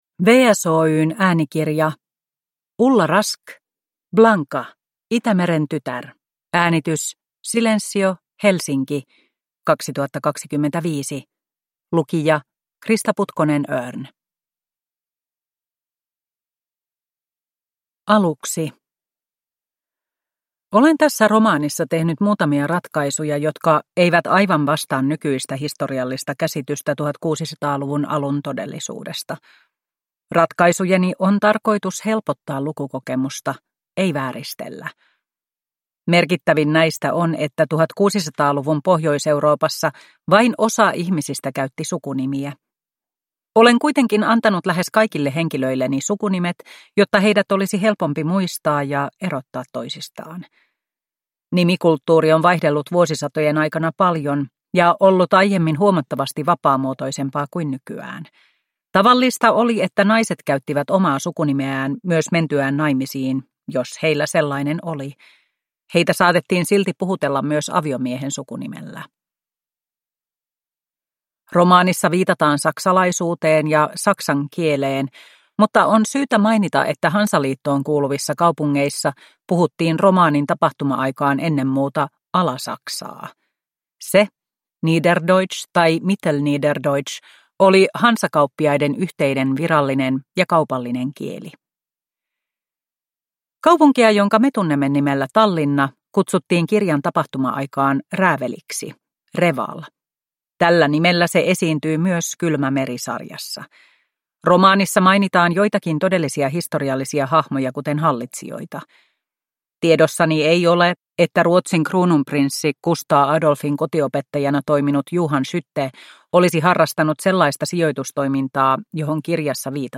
Blanka, Itämeren tytär – Ljudbok